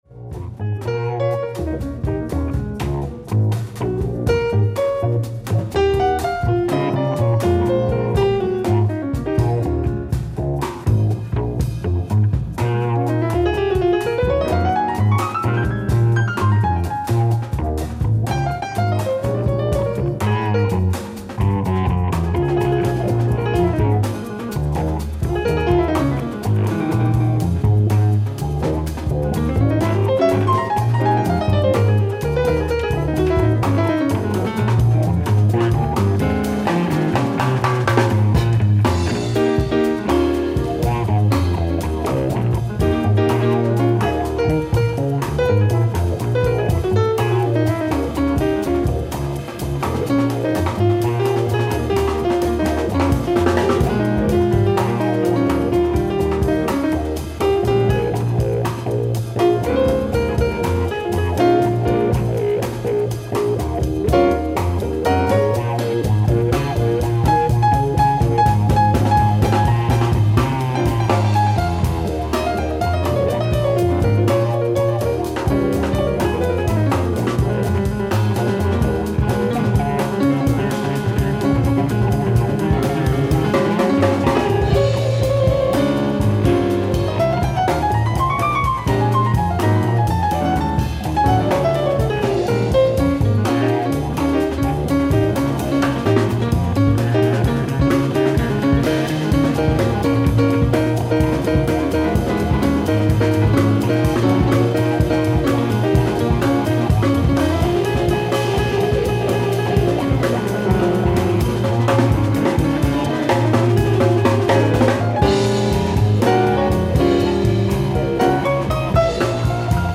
ライブ・アット・ニュルンブルグ、ドイツ 11/02/2000
※試聴用に実際より音質を落としています。